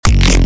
bass house one shots
Vortex_Basses_F#_23